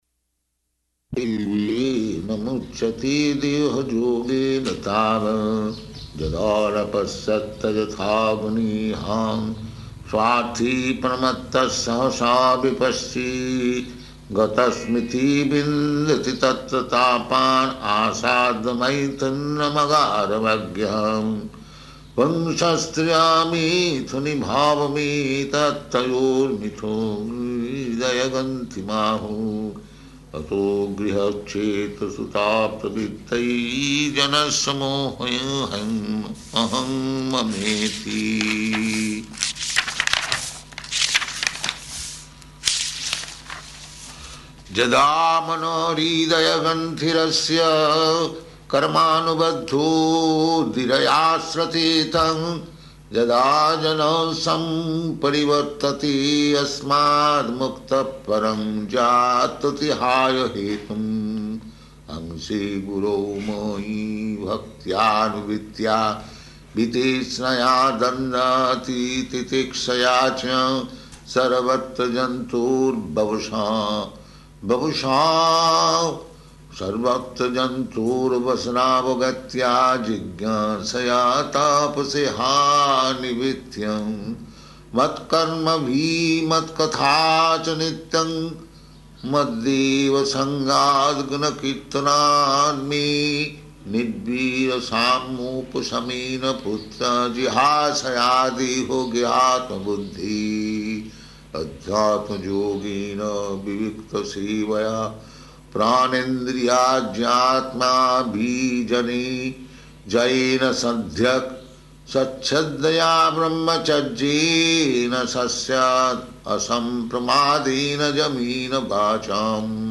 Chanting Śrīmad-Bhagavatam 5.5.6–16
Location: Delhi